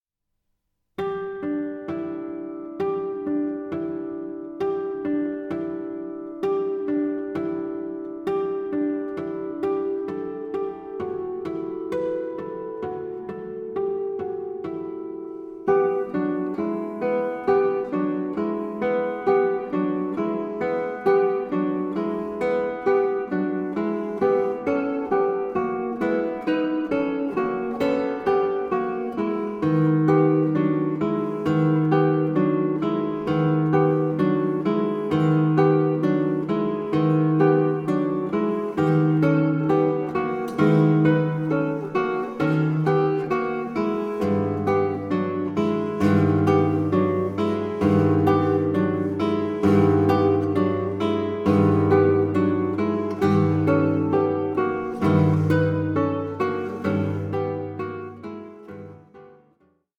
SOLO GUITAR MASTERPIECE
Guitarist
clamourous